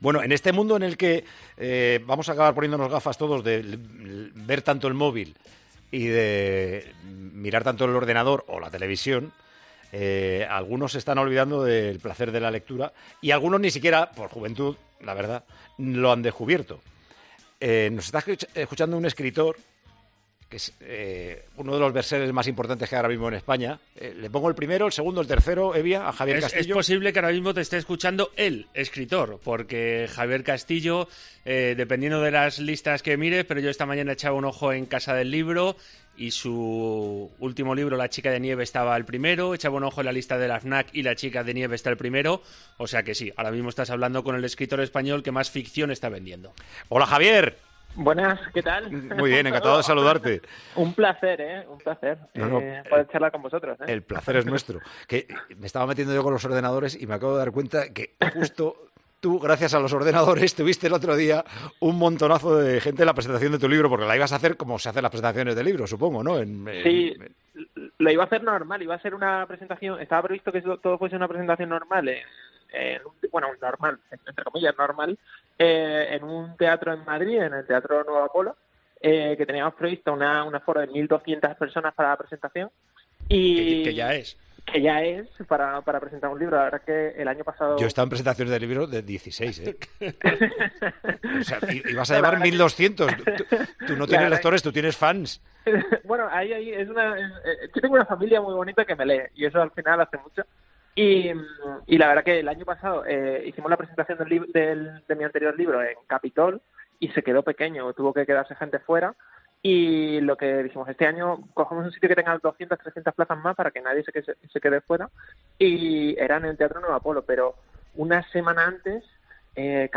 En tiempos de cuarentena, recomendamos 'La chica de nieve', de Javier Castillo, uno de los autores de moda, con el que charlamos este sábado.
Con Paco González, Manolo Lama y Juanma Castaño